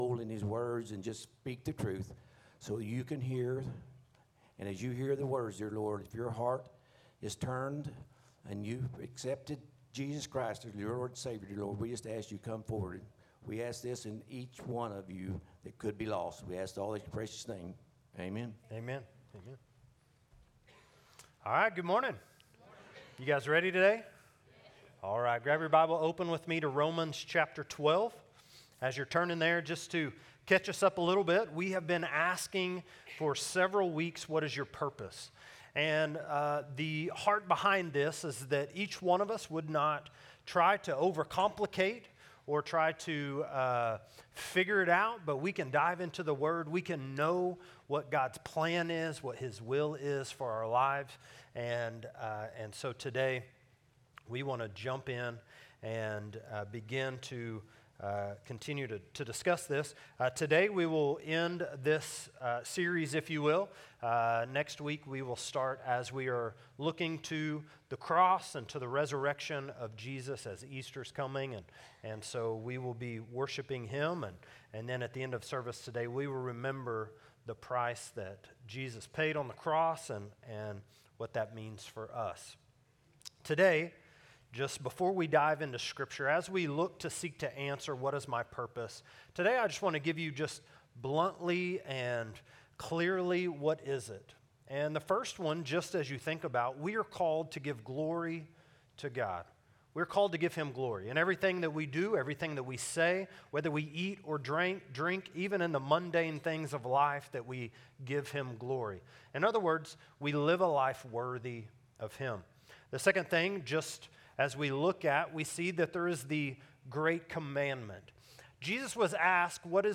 A message from the series "What is your Purpose?." Title: Made for a Purpose: In the Hands of the Potter Scripture: Jeremiah 18:1-6 [CCLI #: 58367]